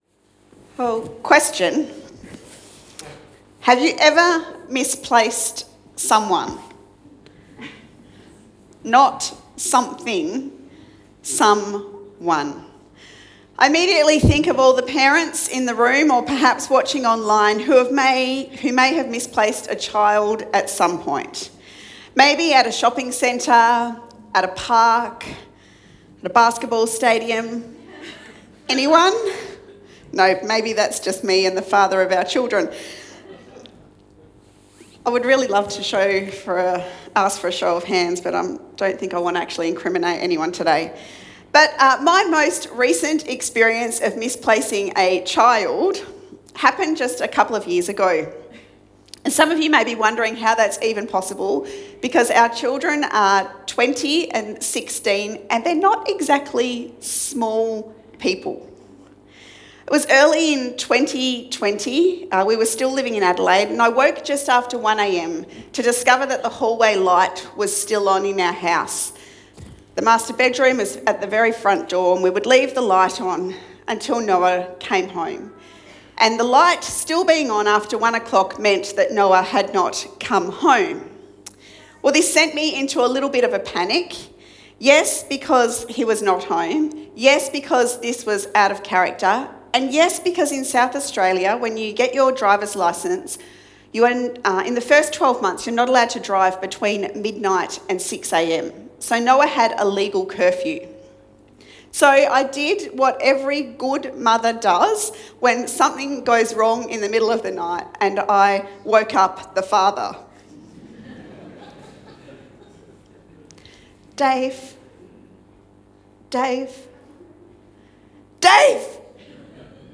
Easter Sunday 2023 and we Crown Him with Glory.
Sermon